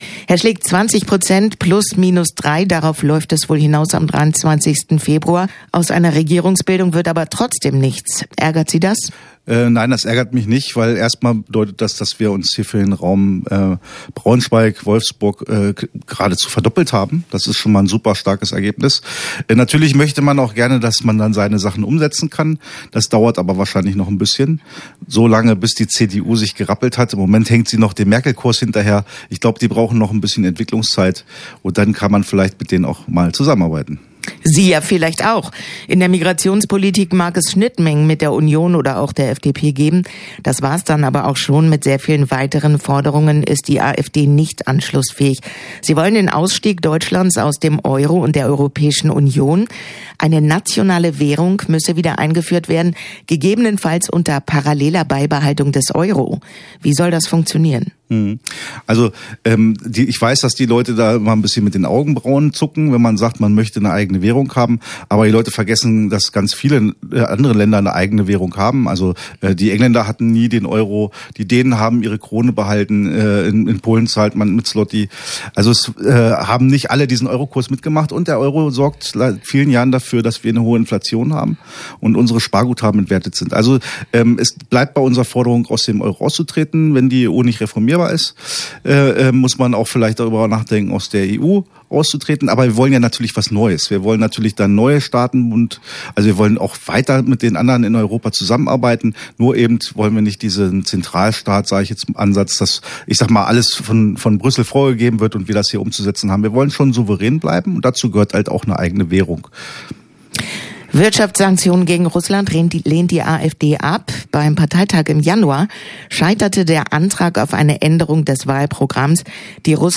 Direktkandidaten zur Bundestagswahl